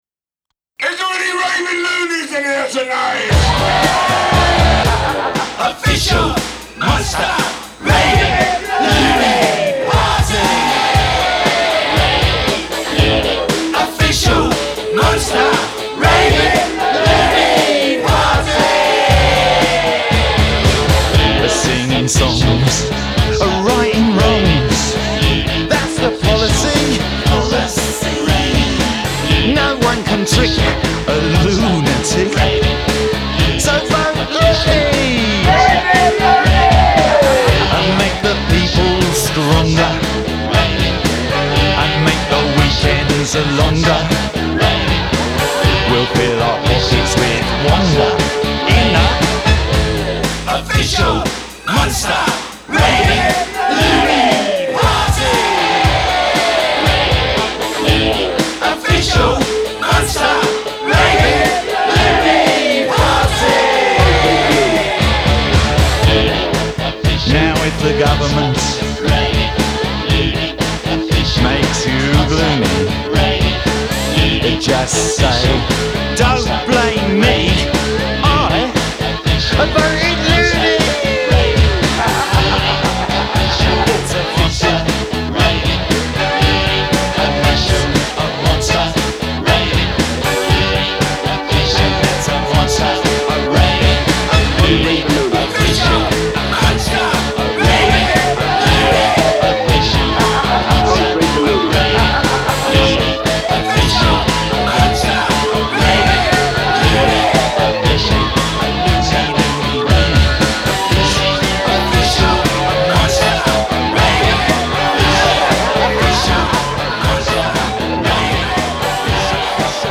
in his recording studio in 1994